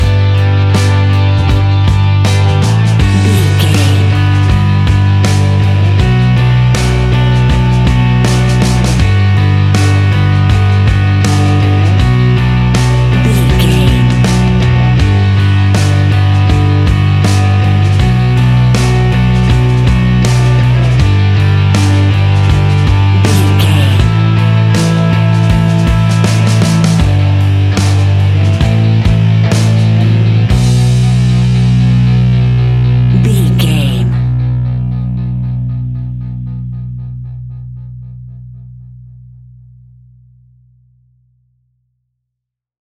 Aeolian/Minor
guitars
hard rock
blues rock
distortion
instrumentals
Rock Bass
Rock Drums
heavy drums
distorted guitars
hammond organ